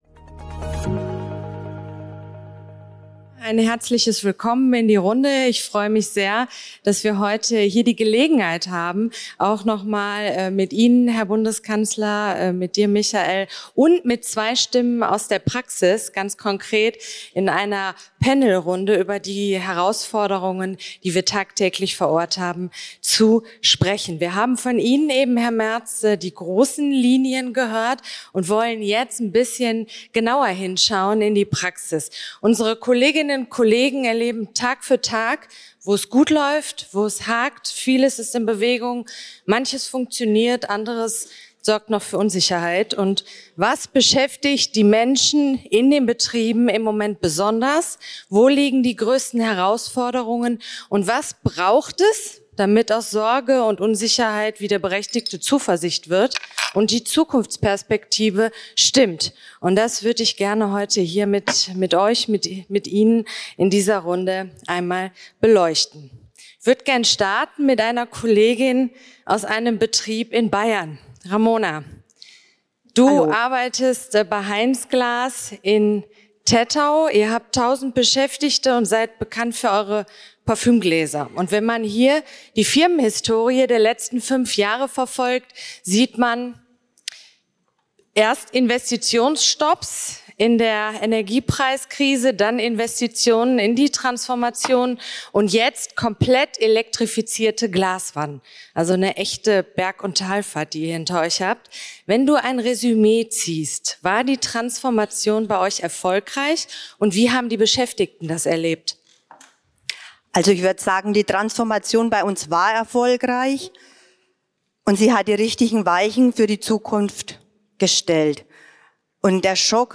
beim 8. Ordentlichen Gewerkschaftskongress